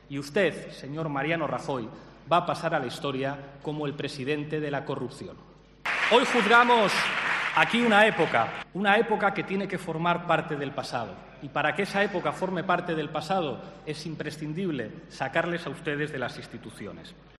Con una tono casi didáctico, Iglesias ha aprovechado su turno durante el debate de la moción de censura de Unidos Podemos contra Rajoy para hacer un repaso de diversos acontecimientos históricos que, según dice, sirven muy bien para entender un modelo de Gobierno basado en "exigir a los ciudadanos lo que perdonan a los amigos".